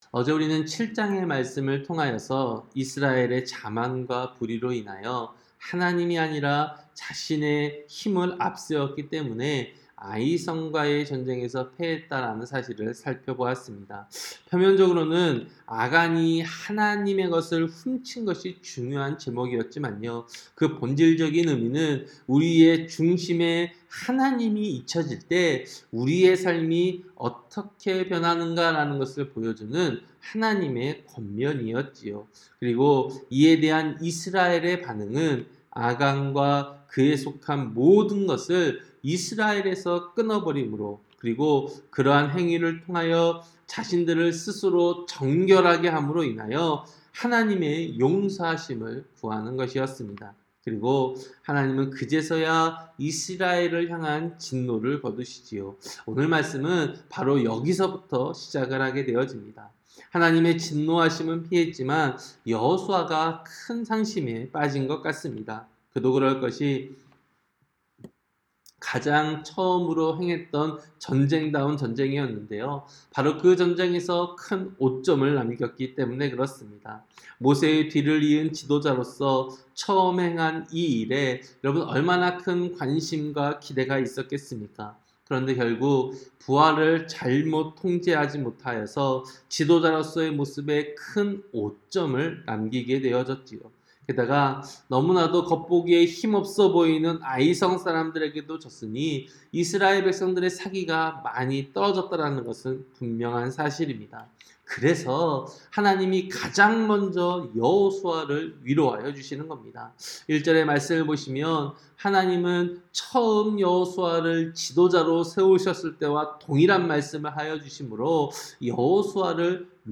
새벽설교-여호수아 8장